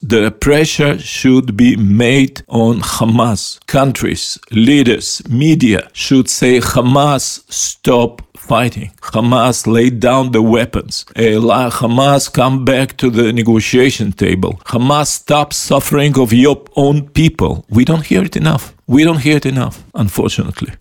O svemu smo u Intervjuu tjedna Media servisa razgovarali s izraelskim veleposlanikom u Hrvatskoj Garyjem Korenom koji je poručio: "Mi nismo ludi ljudi, ne želimo još desetljeća nasilja; lideri trebaju glasno reći Hamasu da je dosta!"